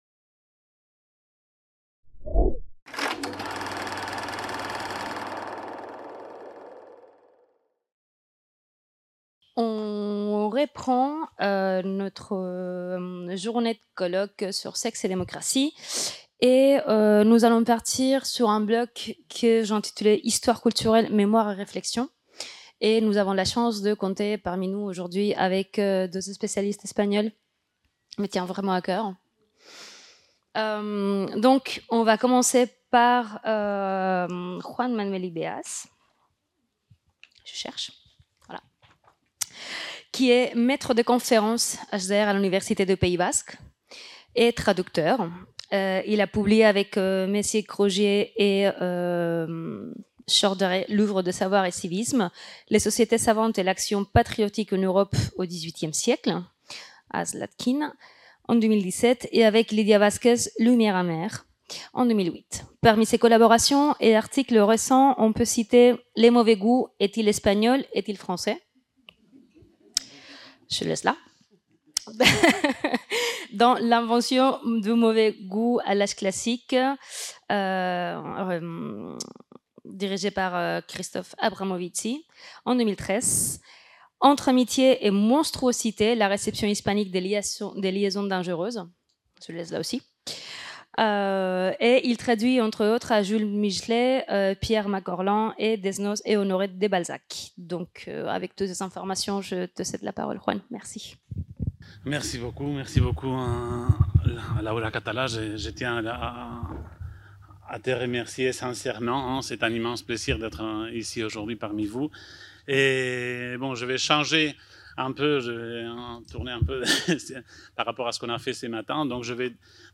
Troisième session de la journée Sexe et démocratie. De l'enjeu du consentement, qui s'est tenue le 30 mai 2024 dans le Hall de la FMSH